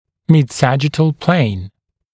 [mɪd’sæʤɪtl pleɪn][мид’сэджитл плэйн]срединно-сагиттальная плоскость